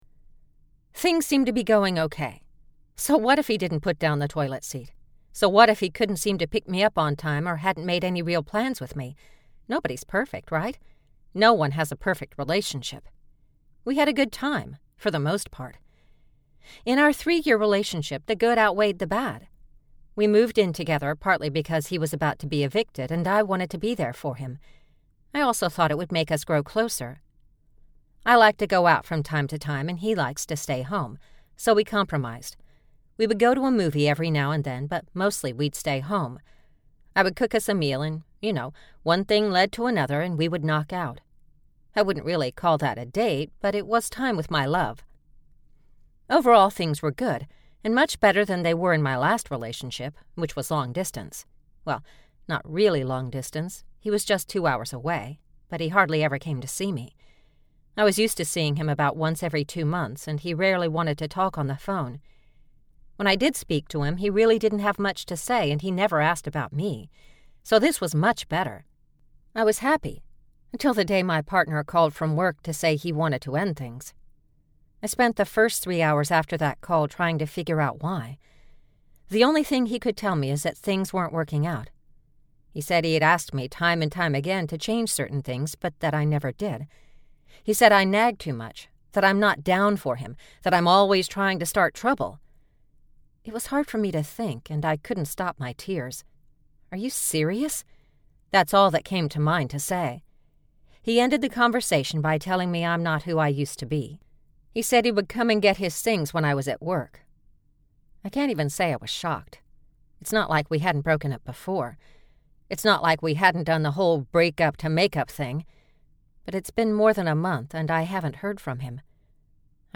Love Lost, Love Found - Vibrance Press Audiobooks - Vibrance Press Audiobooks